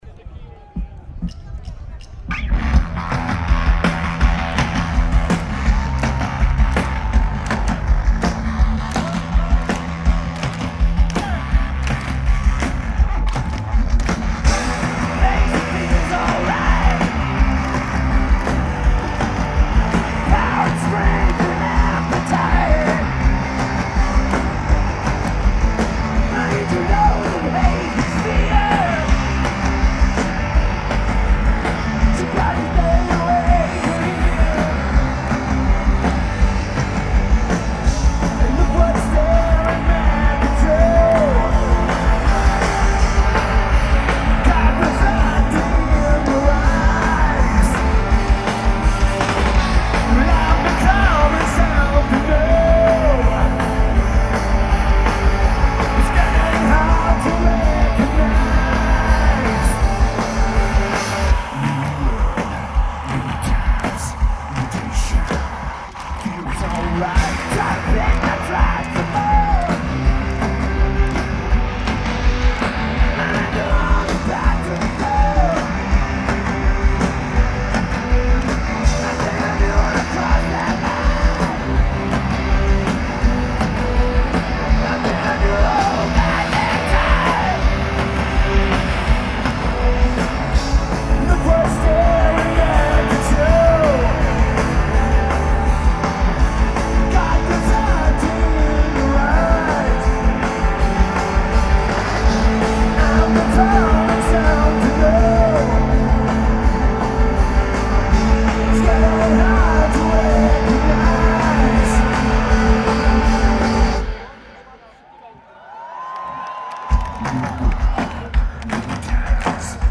Lisbon, Portugal Portgula
Lineage: Audio - AUD (CA-11s + CA9200 + Zoom H2n)